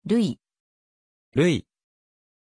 Pronunciation of Lewie
pronunciation-lewie-ja.mp3